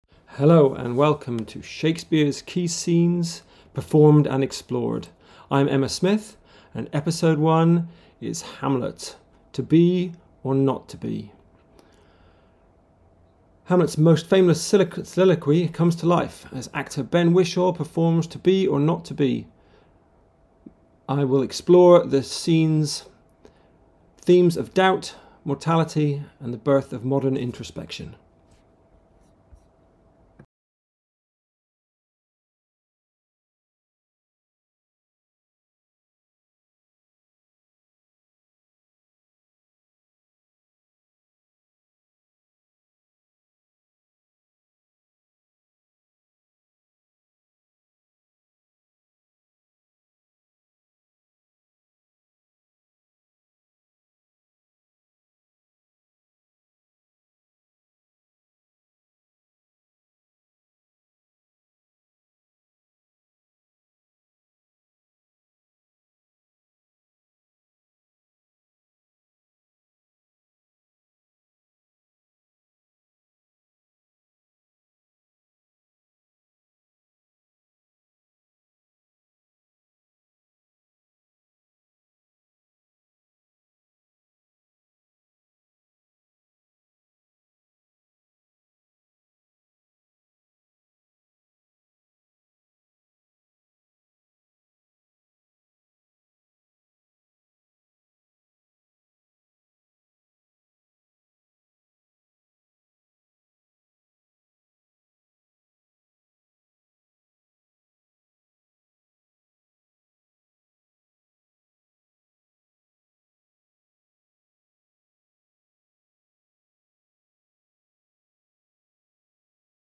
Actor Ben Whishaw delivers a compelling performance that highlights the prince’s inner turmoil, capturing the quiet tension between action and paralysis. His intimate reading brings out the philosophical weight of the speech while keeping its emotional fragility at the forefront.